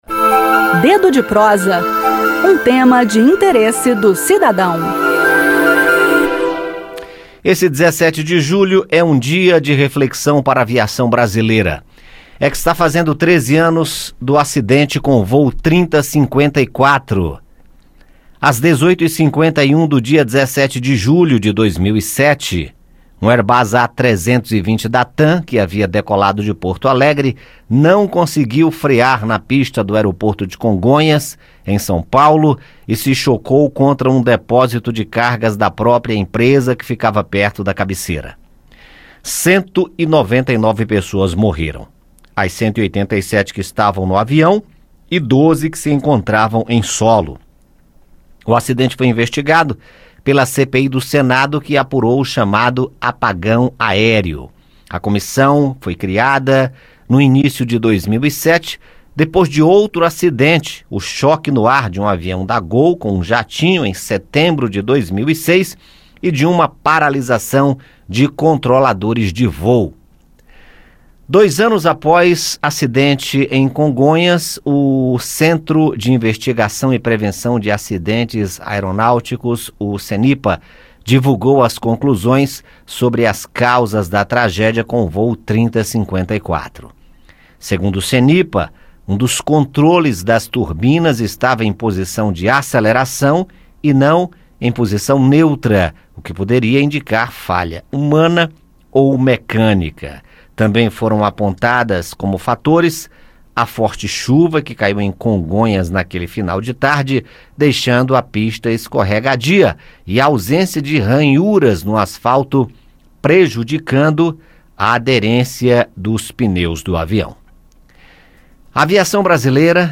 relembra o acidente no bate-papo do Dedo de Prosa desta sexta-feira (17).